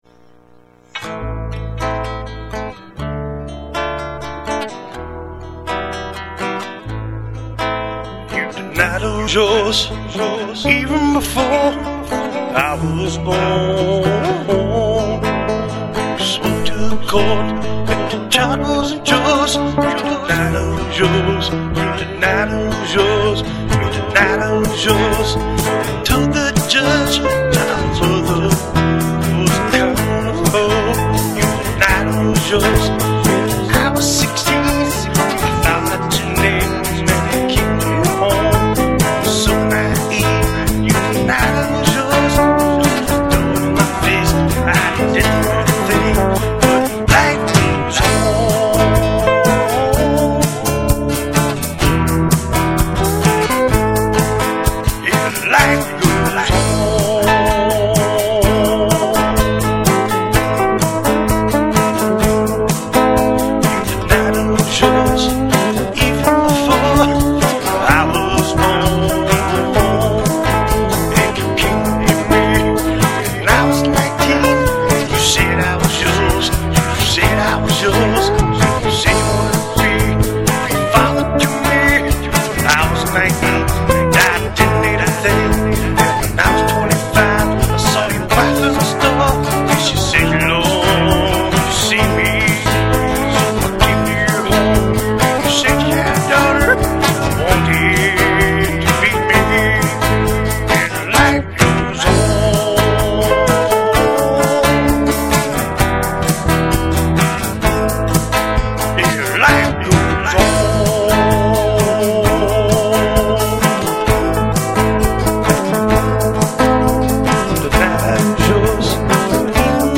Guitar&Music